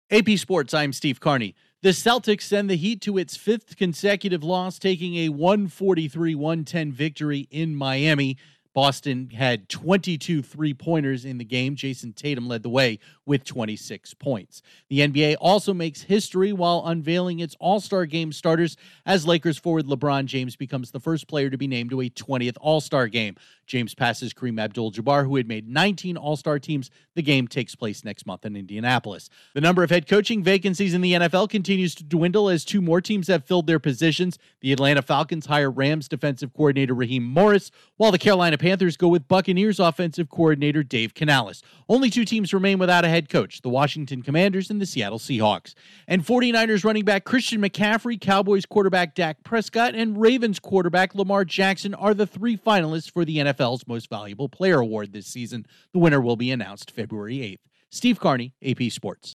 Sports News from the Associated Press / The latest in sports